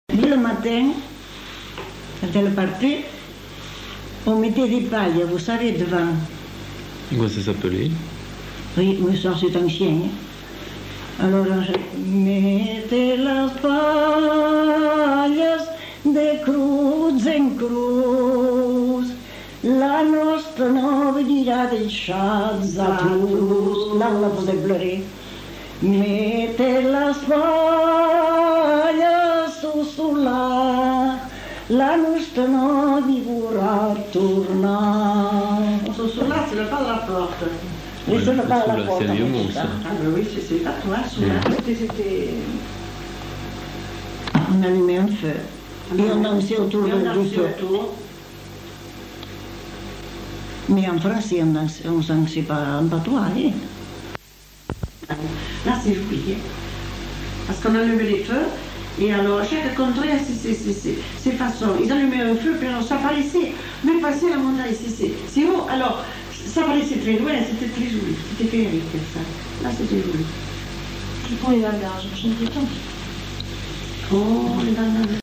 Genre : chant
Effectif : 1
Type de voix : voix de femme
Production du son : chanté